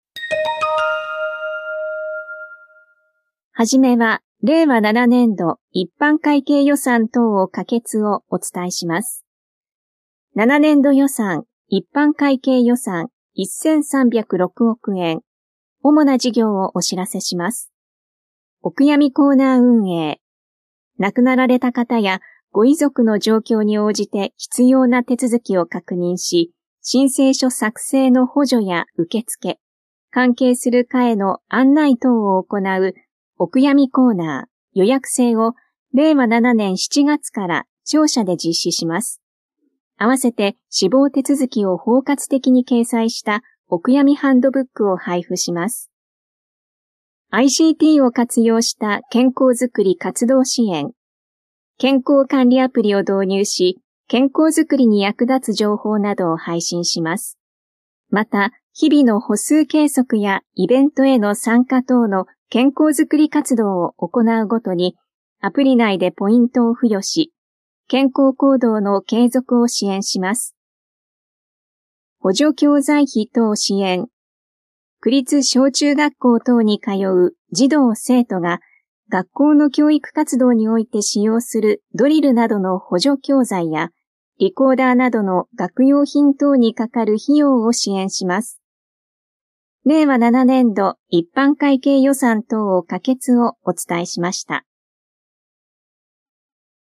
声の区議会だよりの音声読み上げデータです。